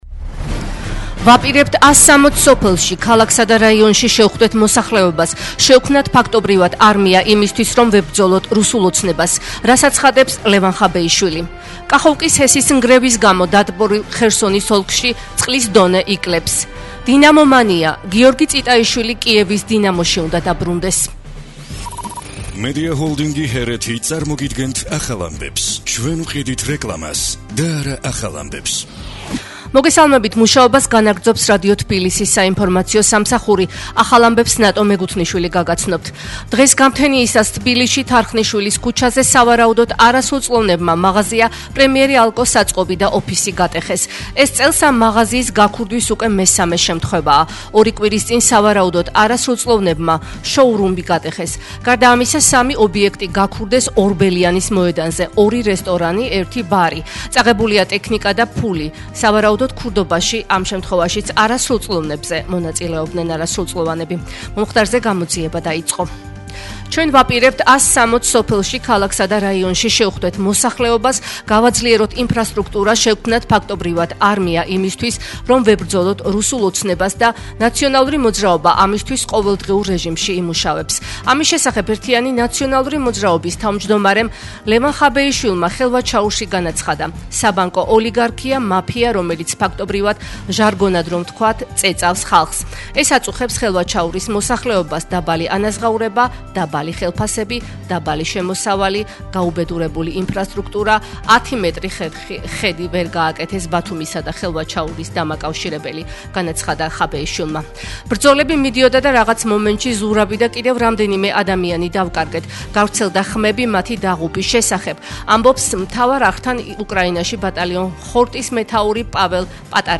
ახალი ამბები 12:00 საათზე